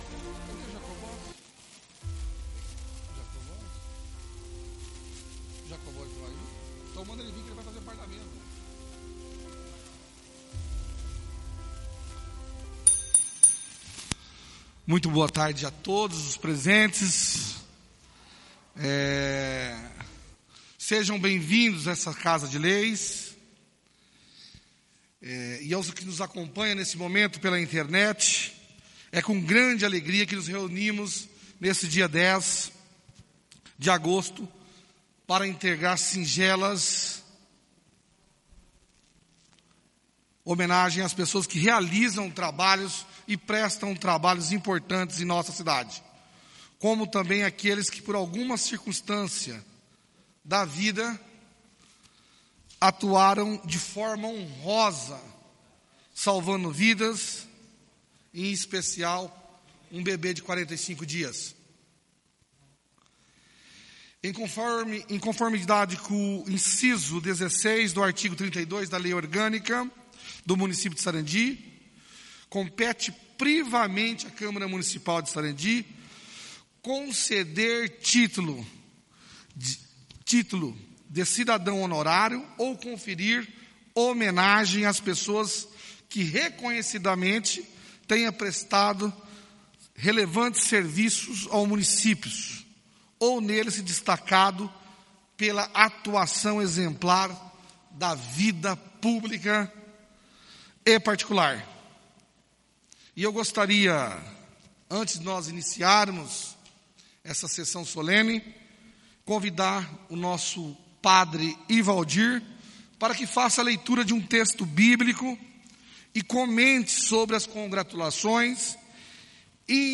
O Presidente da Câmara Municipal de Sarandi-PR. Sr. Eunildo Zanchim, verificando a existência de quórum legal dá início à 1ª Reunião Solene do dia 10/08/2020.
Por se tratar de Reunião Solene, não há leitura de texto bíblico e sim a entonação aos hinos de Sarandi e Nacional.